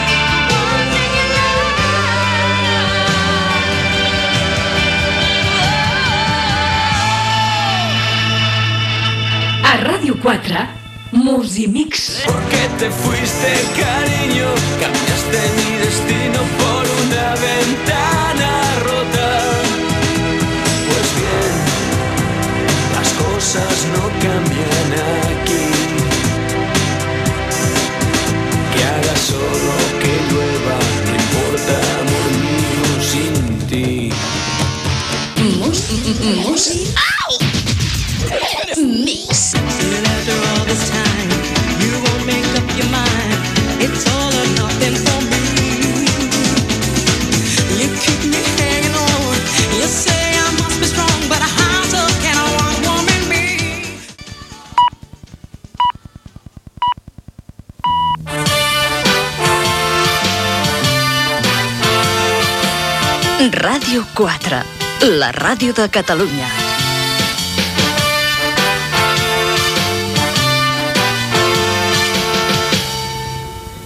tema musical, senyals horaris i indicatiu de l'emissora
Musical
Programa musical sense presentador